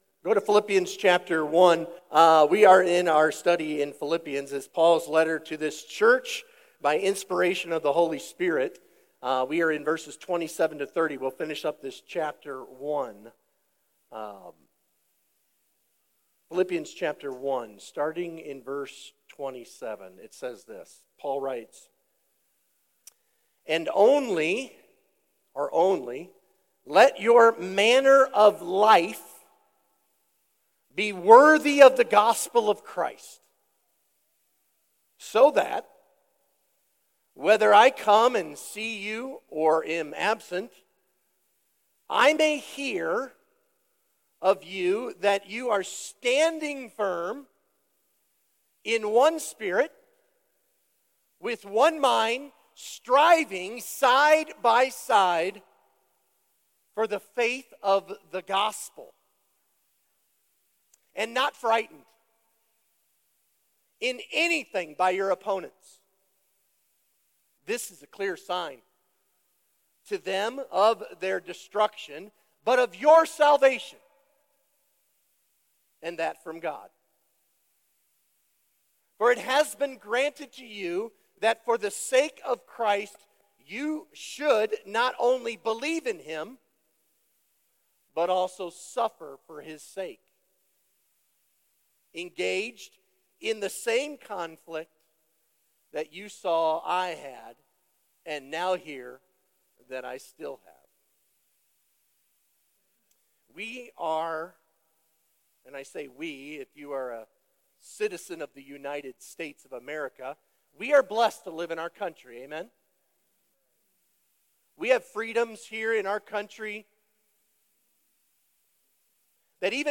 Sermon Questions Read through Philippians 1:27-30.